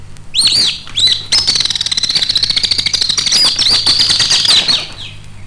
Martinet Sound Effect
Download a high-quality martinet sound effect.
martinet.mp3